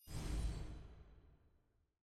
sfx-s17-hub-tracker-highlight.ogg